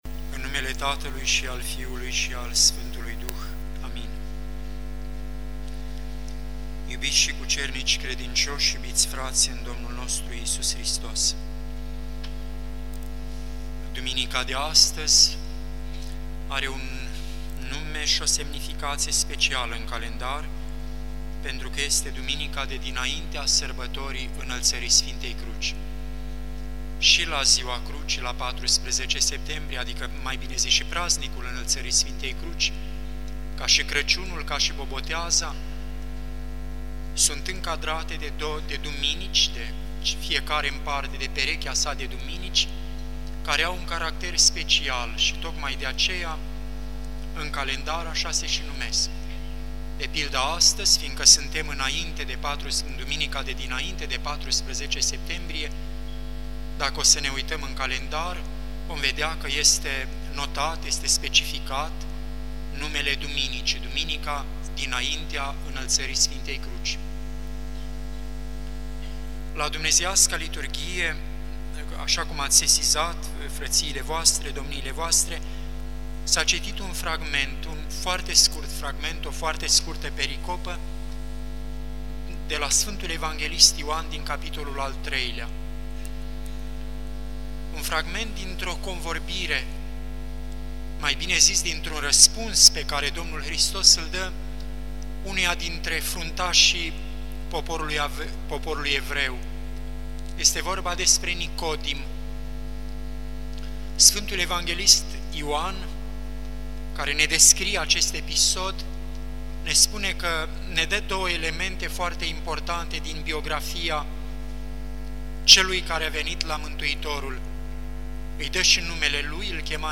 Cuvinte de învățătură Predică la Duminica dinaintea Înălțării Sfintei Cruci